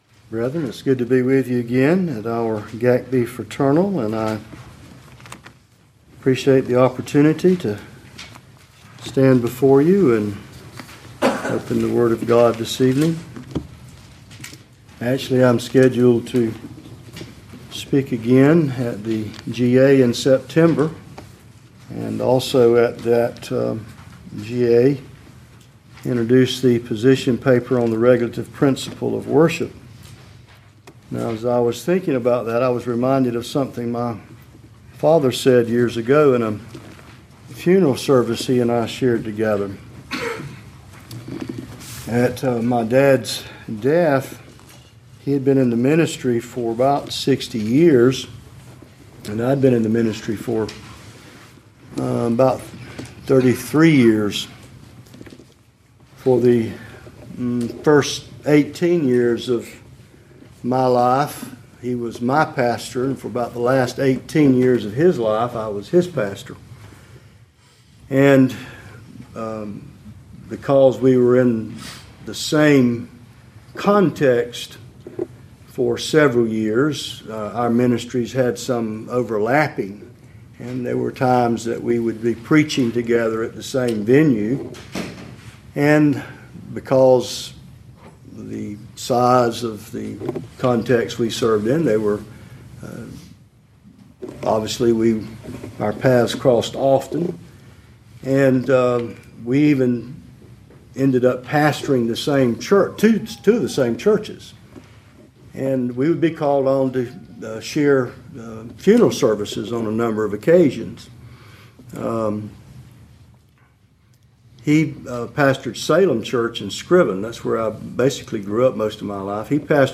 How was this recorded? We welcome you to browse our library of past messages from our pastors’ fraternals.